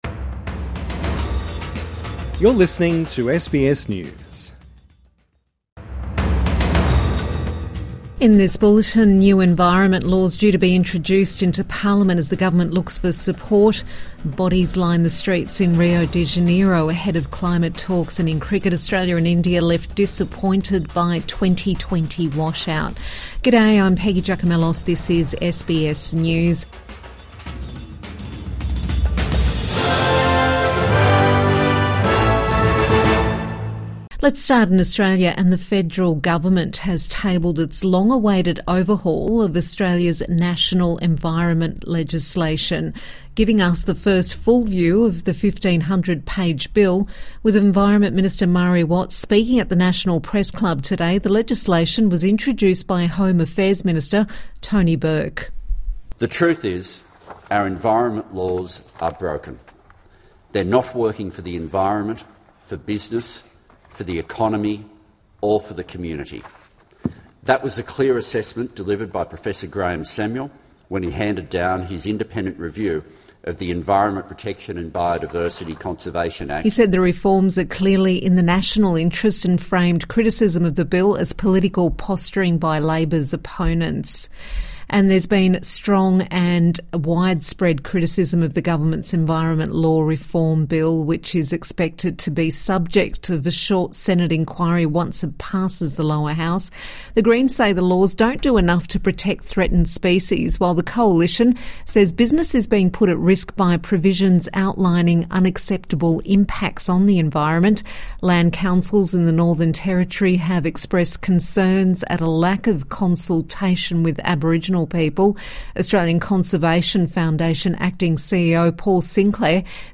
New environment laws due to be introduced into parliament |Midday News Bulletin 30 October 2025